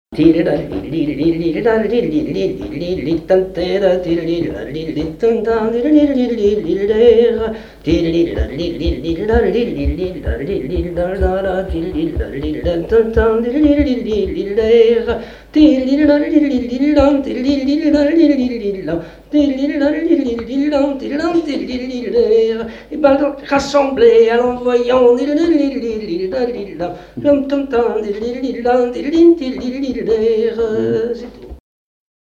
danse : pas d'été
Répertoire de chansons populaires et traditionnelles
Pièce musicale inédite